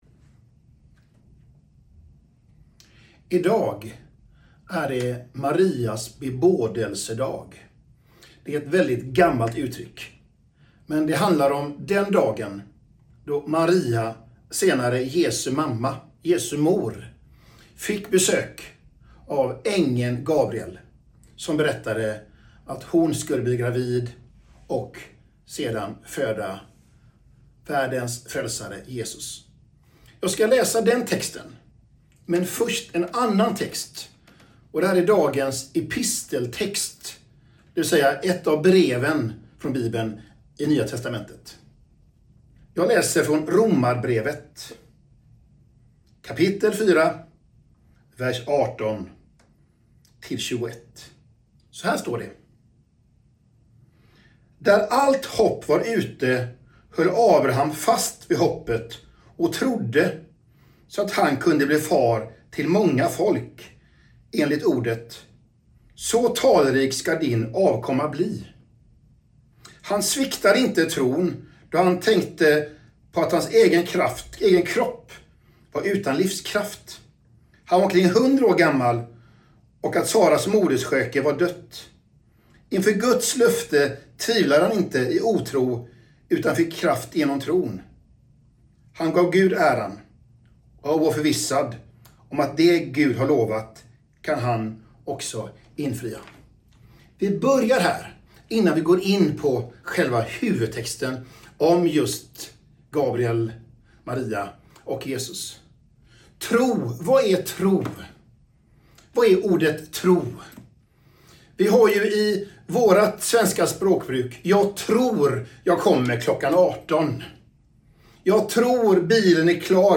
Predikan, 2024-04-21